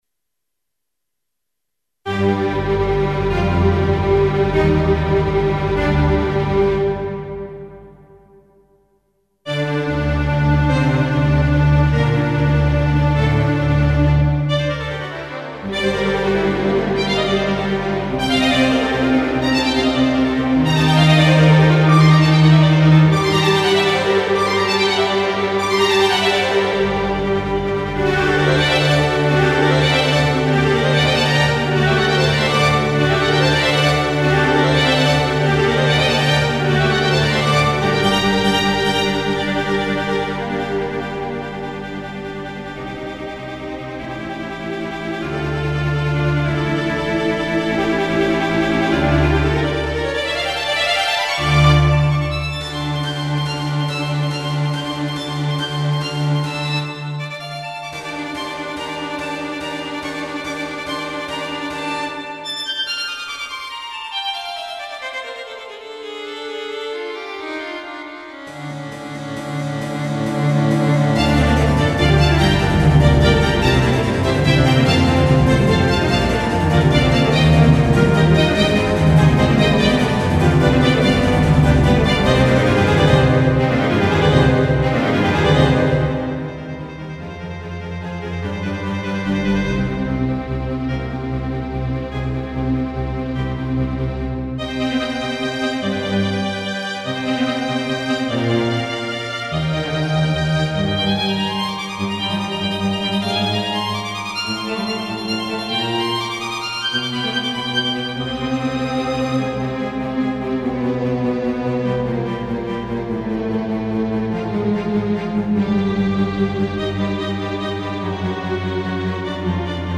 made with "Miroslav Philharmonik"
CLASSICAL MUSIC